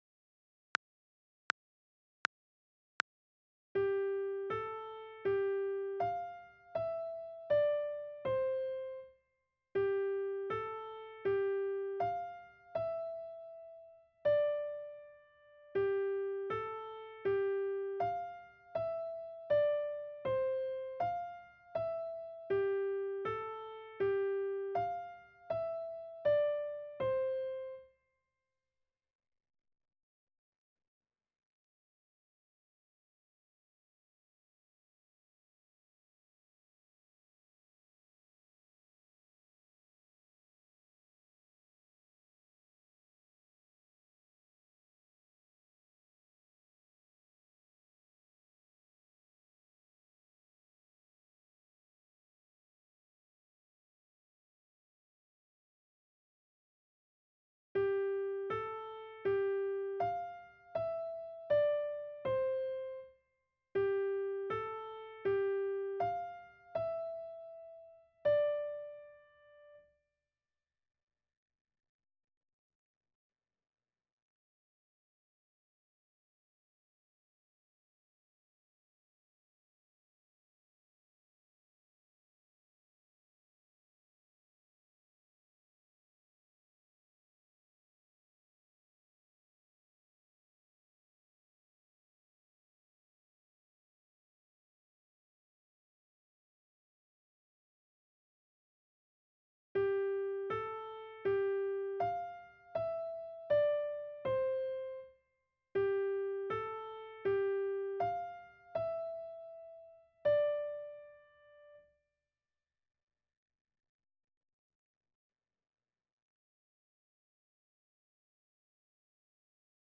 ※課題の中には、無音部分が入っていることもあります。